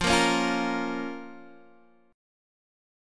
Fsus4#5 chord